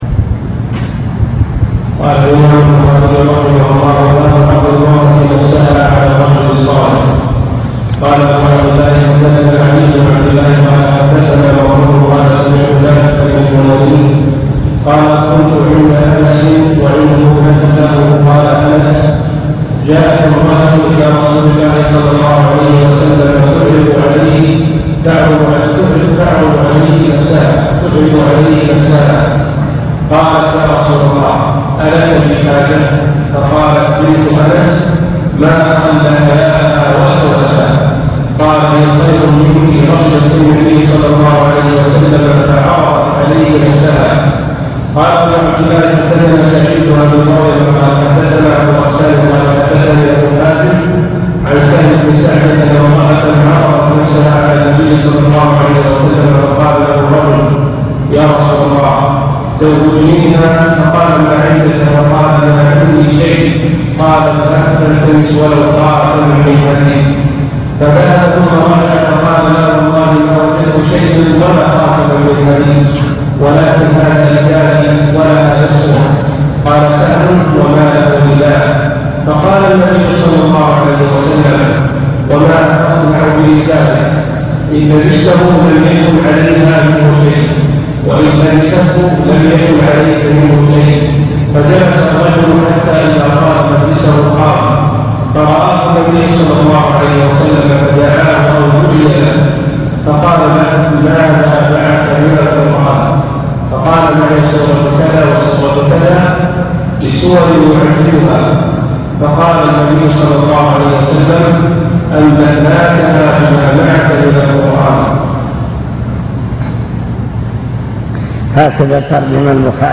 المكتبة الصوتية  تسجيلات - كتب  شرح كتاب النكاح من صحيح البخاري